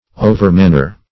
Search Result for " overmanner" : The Collaborative International Dictionary of English v.0.48: Overmanner \O"ver*man`ner\, adv. In an excessive manner; excessively.